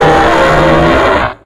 Audio / SE / Cries / EXPLOUD.ogg